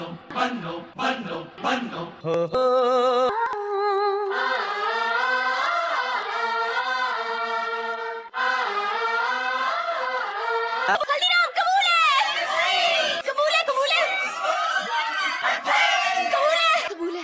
Bollywood Song
Other ambigous sounds